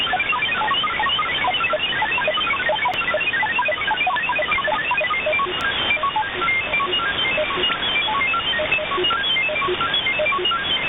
File:CIS MFSK 21-13 21-to-13 sample.ogg - Signal Identification Wiki
MFSKMultiple Frequency Shift-Keying-21-13 signal